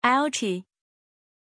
Aussprache von Altti
pronunciation-altti-zh.mp3